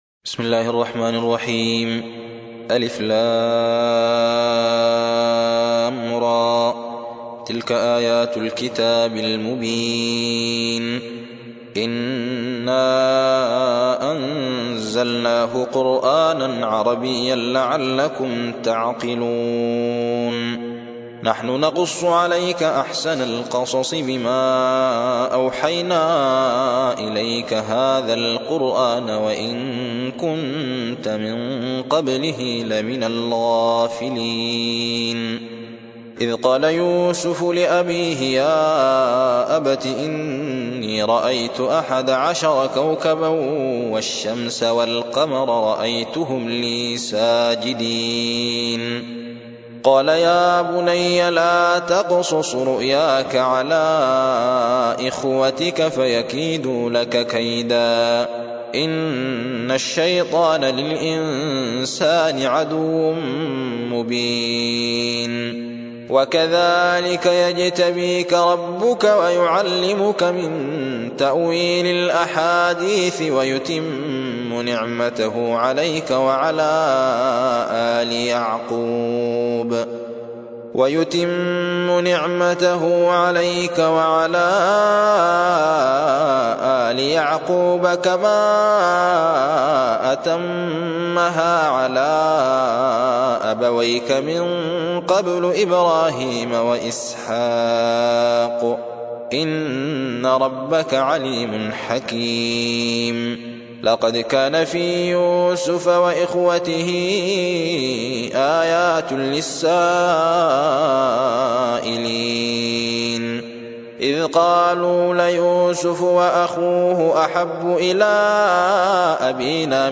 12. سورة يوسف / القارئ